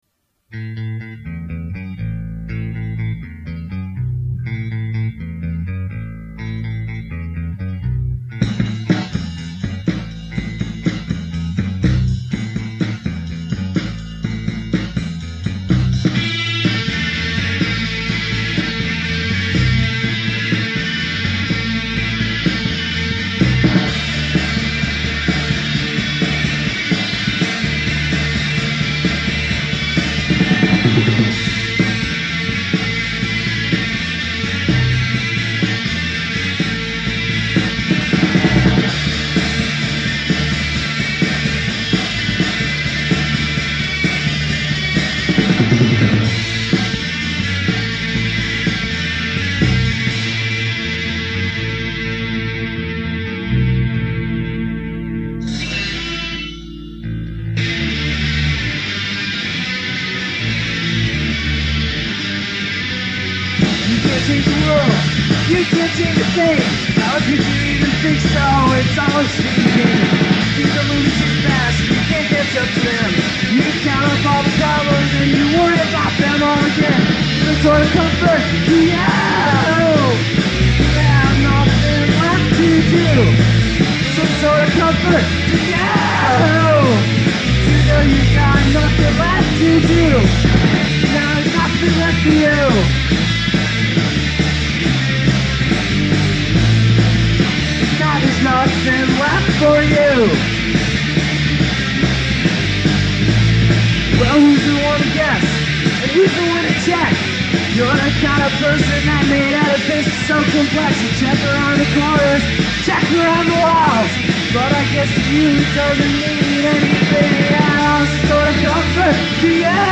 recorded on a four-track in late 1992
It was, I think, the first song we recorded together, though the four-track could only record two channels at a time so we didn’t all record together. We did bass and drums for each track first; guitar and voice came later.